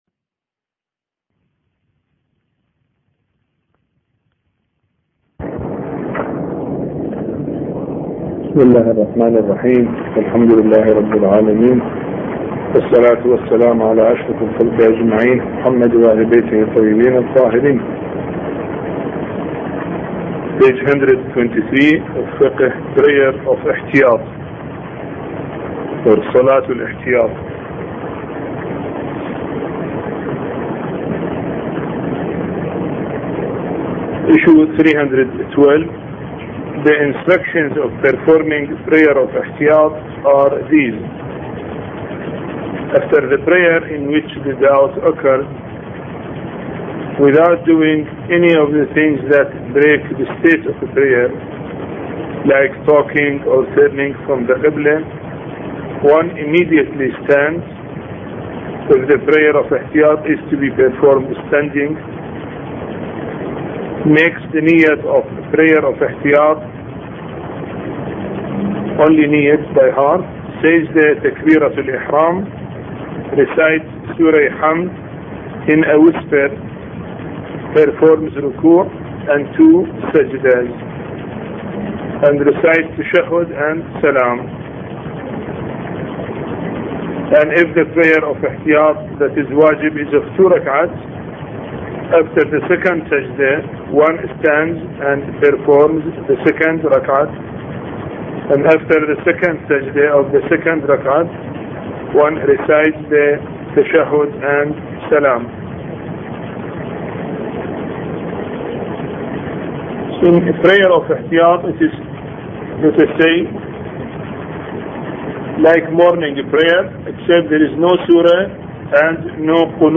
A Course on Fiqh Lecture 22